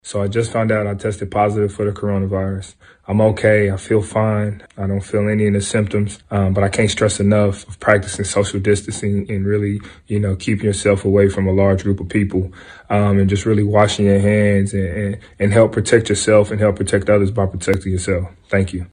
In a statement on social media, Smart explains.